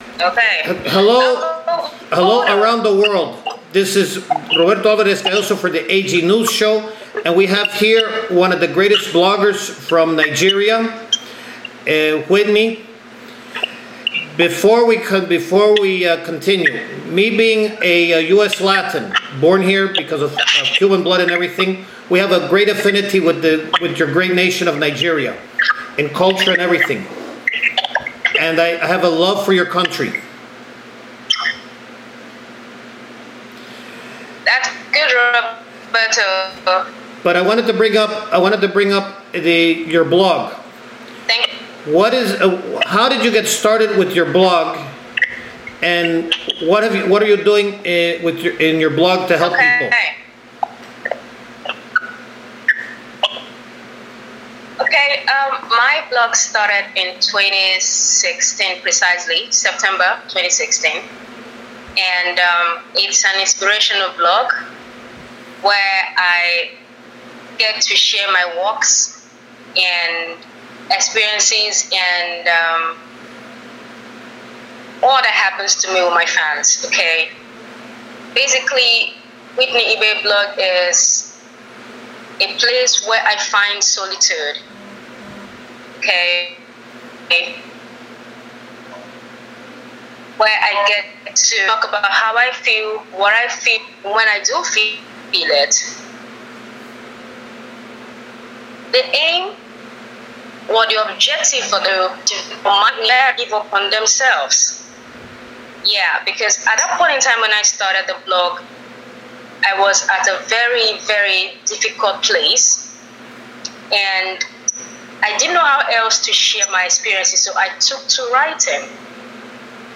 The Complete Interview